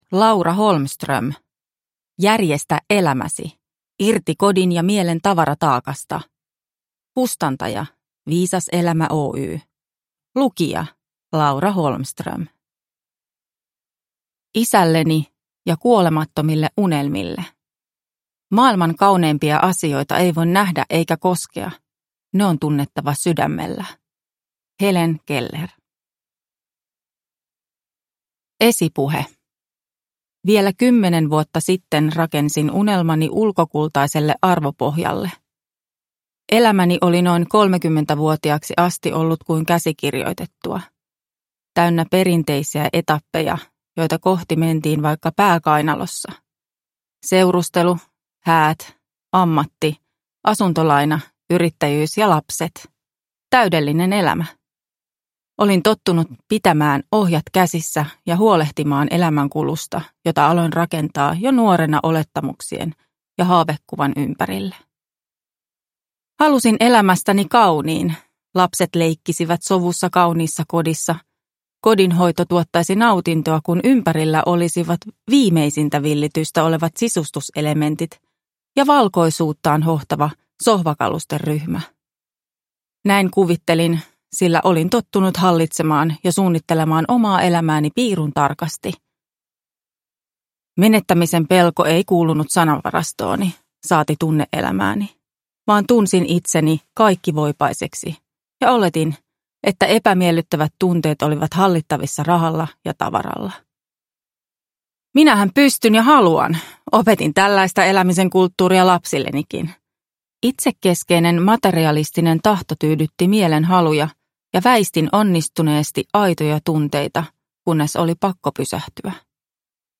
Järjestä elämäsi – Ljudbok